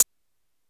Southside Closed Hatz (6).WAV